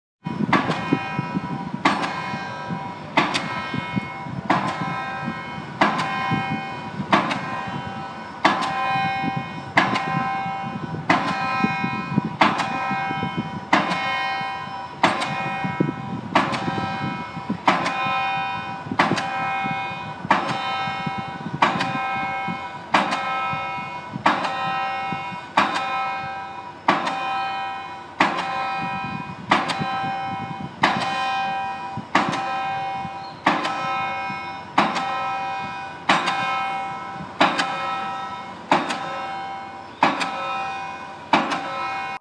Manic Church Bell
manic-church-bell.m4a